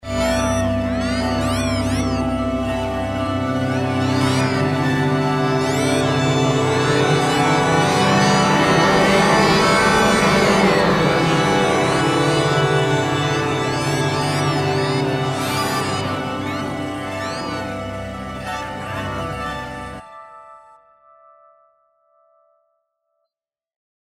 The brightness controls the volume and the pitch, meaning the brightest sources in the image are the loudest and highest frequencies. The data from the three telescopes are mapped to different types of sounds. The X-rays from Chandra sound like a synthesizer, Spitzer’s infrared data are strings, and optical light from Hubble has bell-like tones. The core of the galaxy, its dust lanes and spiral arms, and point-like X-ray sources are all audible features in the sonification of these data.